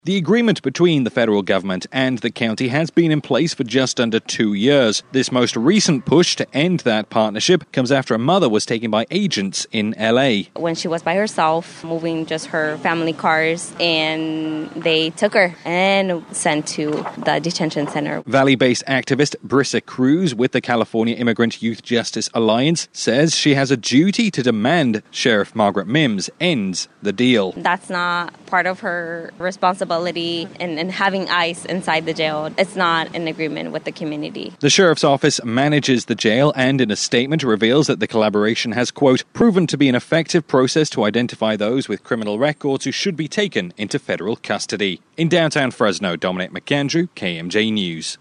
as it aired